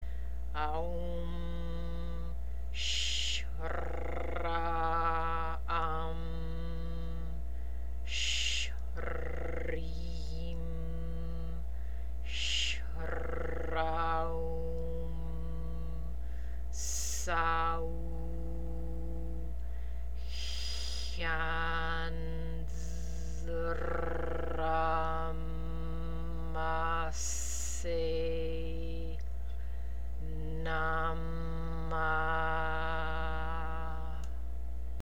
МАНТРА ЗА ЛУНАТА:
AАА-УУУ-MMM ٠ Ш-РР-AА-AА-MM ٠ Ш-РР-ИИ-MM ٠ Ш-РР-AА-УУ-MM ٠ ССА-УУУ ٠ ХХИИ-ЯАА-НН-Д-РР-АА-ММ-ААСЕЙ ٠ НАА-MAА
Артикулирайте "Р" правилно. Звукът "Х", в случая, е гърлен.
2 - Moon Square Mantra.mp3